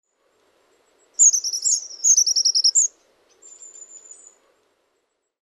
Ääntely: Laulu on kirkas ja korkea tii-tii-tii.